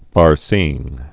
(färsēĭng)